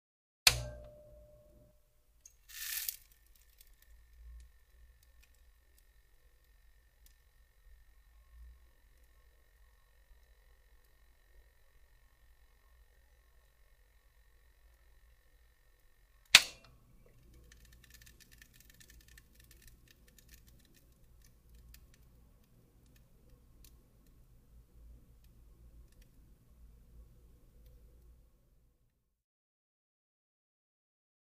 PC Monitor | Sneak On The Lot
PC Monitor; On / Off; Computer Monitor; Power On / Surge / Static / Steady Fan And Hum / Power Off / Static, Close Perspective.